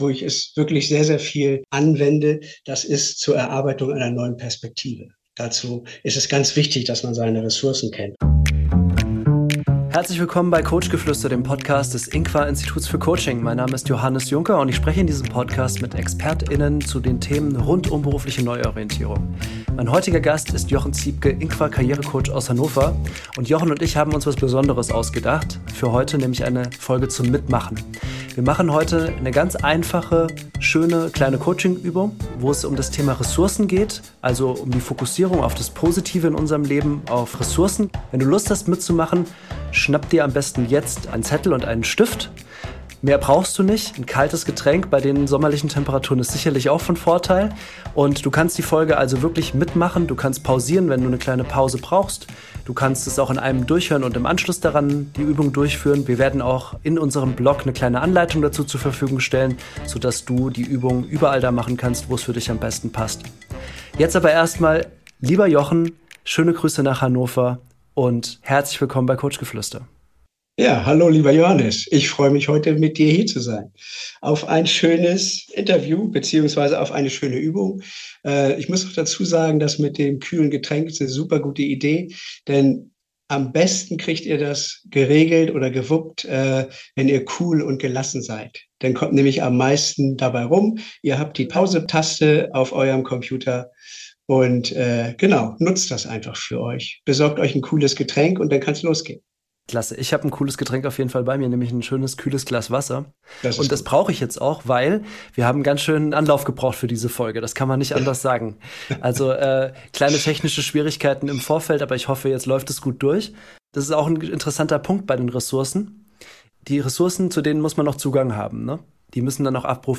Wie das live funktioniert, hörst du in unserem Mitmach-Podcast.